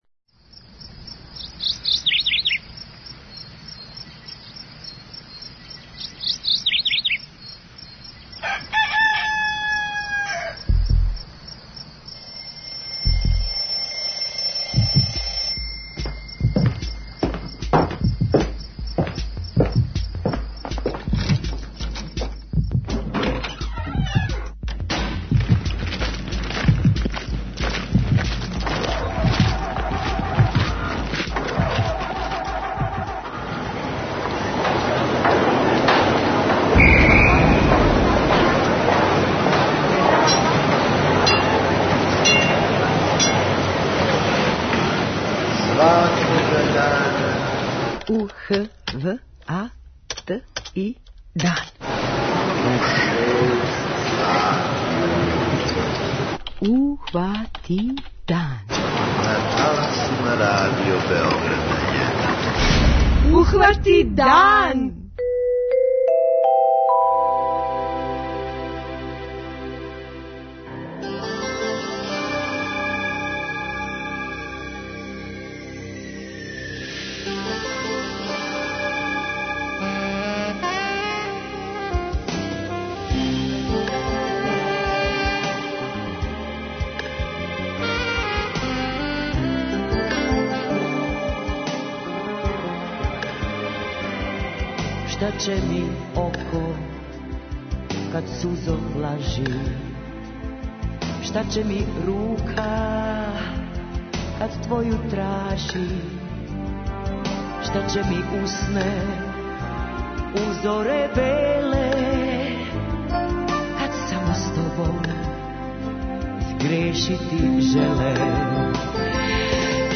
преузми : 29.25 MB Ухвати дан Autor: Група аутора Јутарњи програм Радио Београда 1!